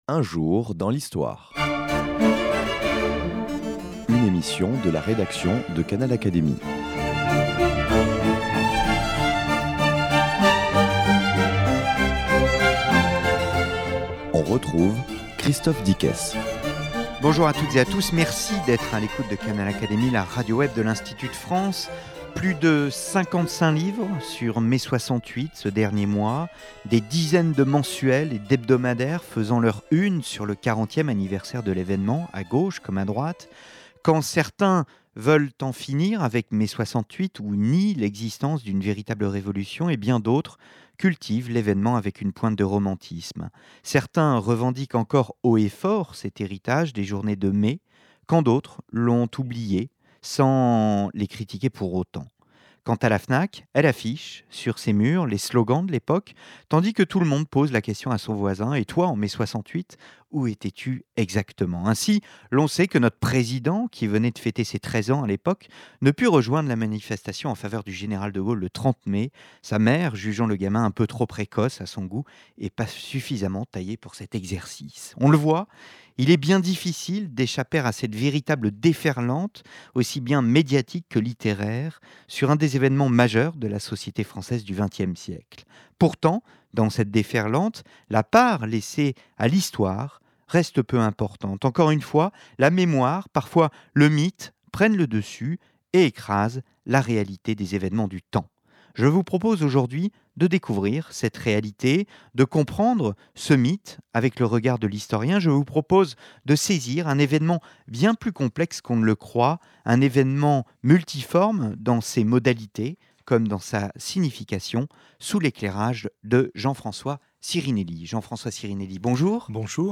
Éminent spécialiste de l’histoire de France du XXe siècle, Jean-François Sirinelli, auteur chez Fayard de « Mai 68, événement Janus » offre au cours de cette émission un regard d’historien sur une période encore controversée. Retour lucide sur l’événement, en ce quarantième anniversaire.